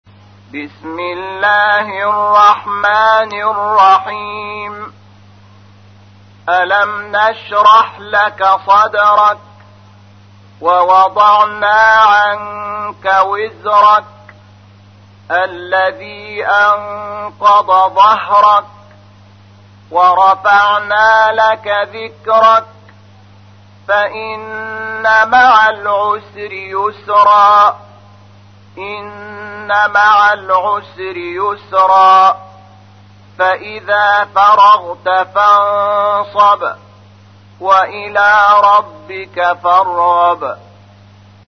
تحميل : 94. سورة الشرح / القارئ شحات محمد انور / القرآن الكريم / موقع يا حسين